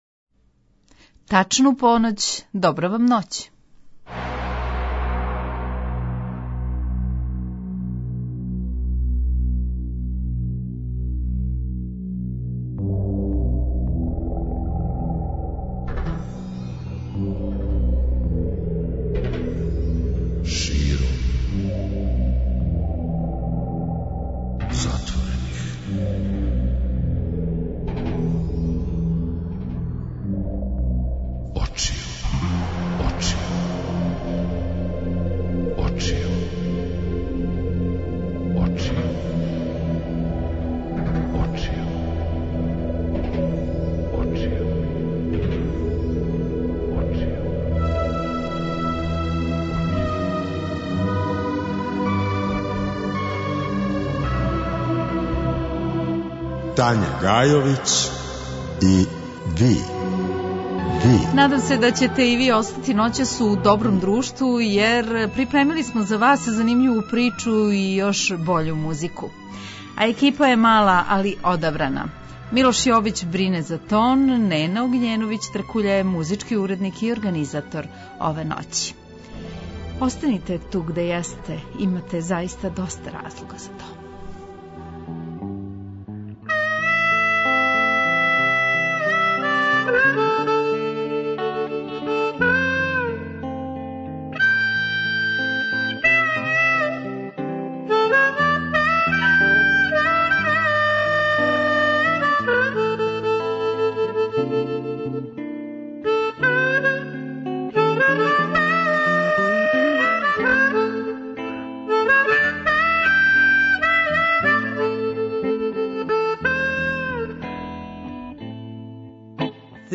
Владо је ексклузивно говорио за Ноћни програм о својој каријери, успесима и неуспесима који су га пратили, сазнаћемо и шта данас ради и можемо ли га скоро видети у Београду.